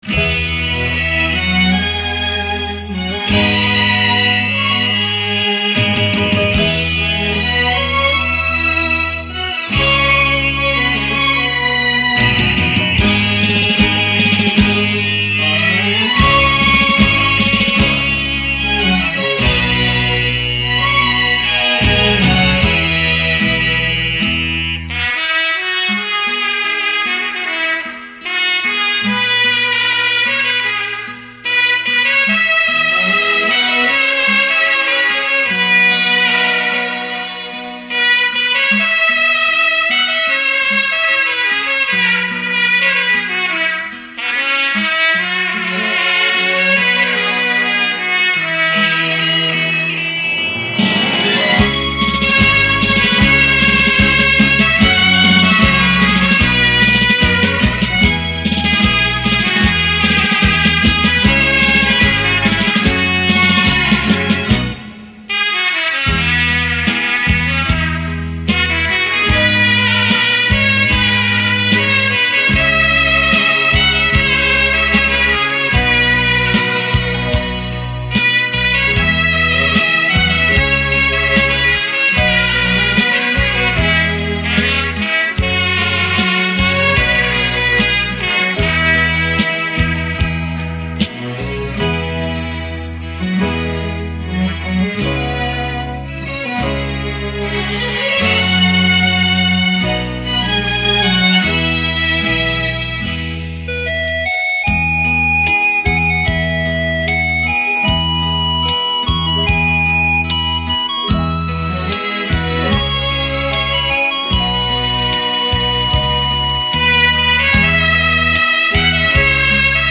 优美清脆的小号激情演绎，再把台语民歌委婉旋律传；